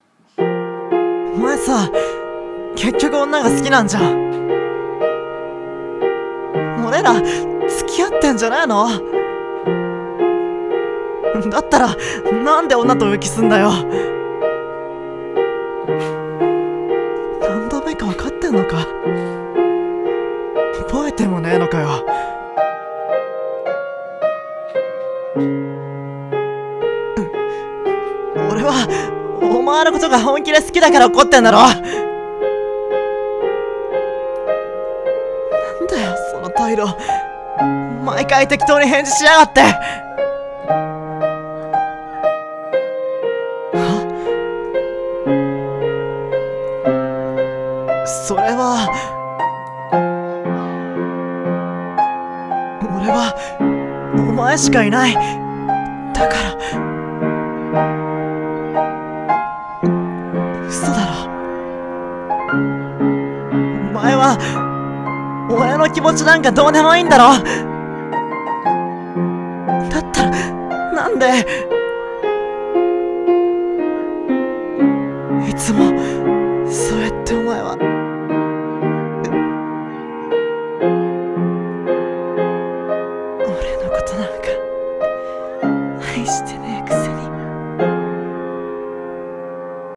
【BL声劇】何度裏切られても·····【二人声劇】